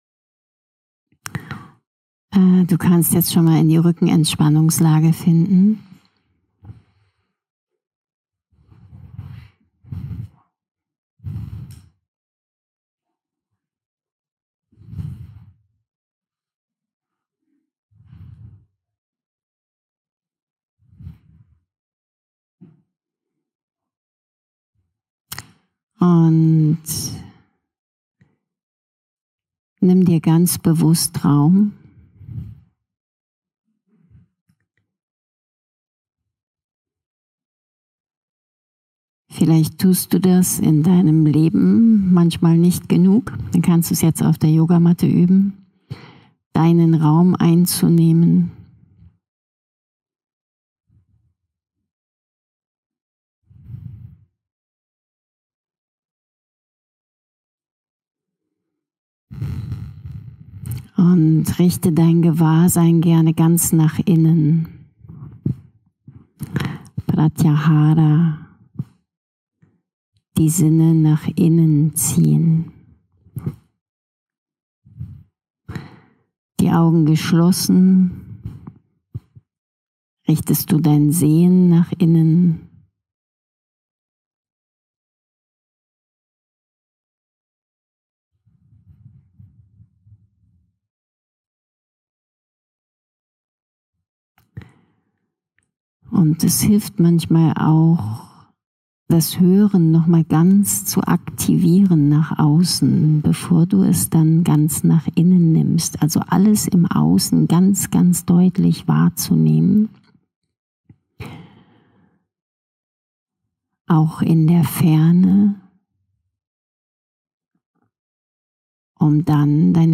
Schau_nach_Innen_und_steigere_deine_Lebensenergie-Tiefenentspannung-Yoga_Vidya_Uebungs_Podcast.mp3